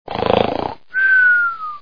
snore.wav